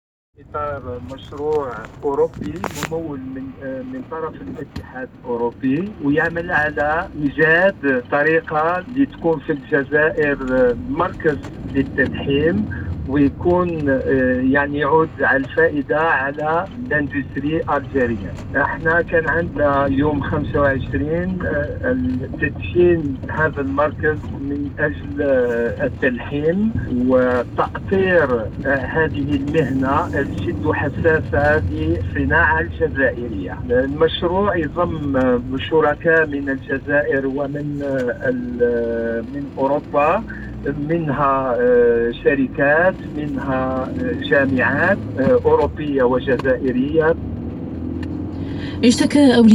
Passage à la radio El Bahdja dans le cadre du projet Erasmus+ SM Weld